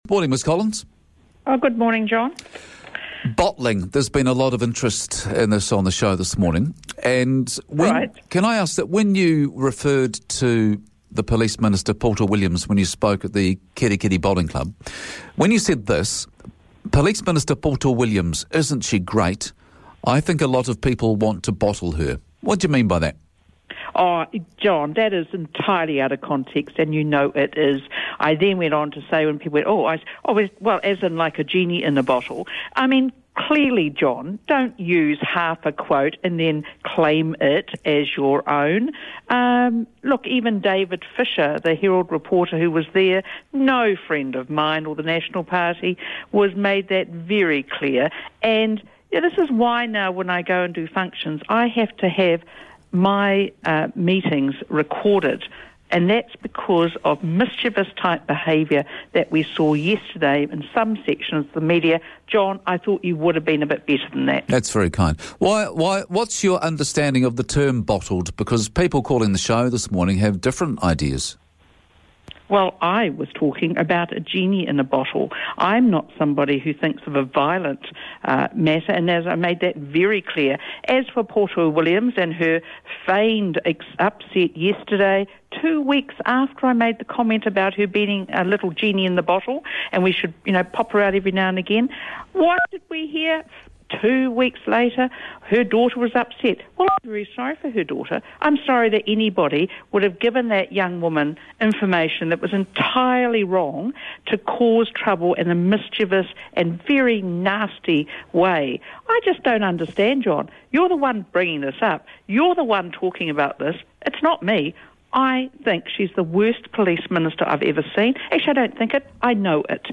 Listeners have described the interview as “fiery”. In terms of the flaccid presentation of most NZ radio and TV perhaps it was.